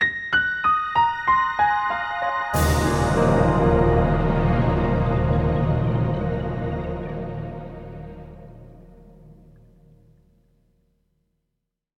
Reload.wav